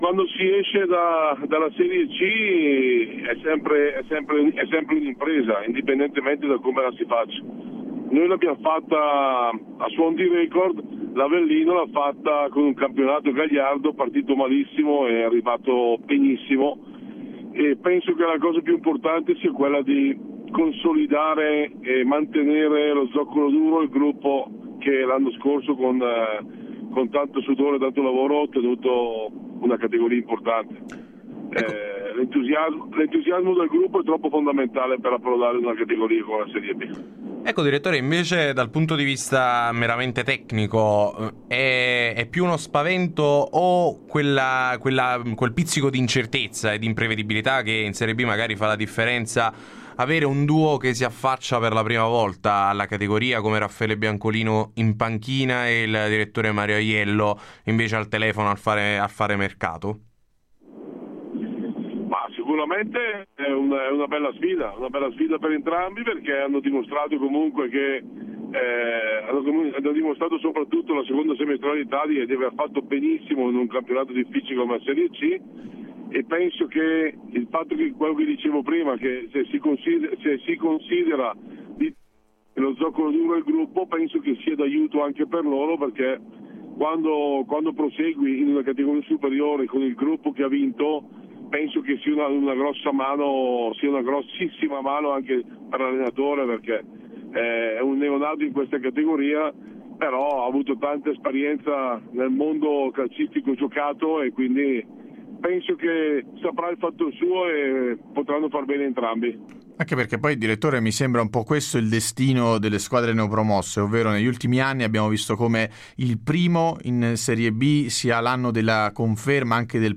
Ospite di Radio Punto Nuovo nel corso de Il Pomeriggio da Supereroi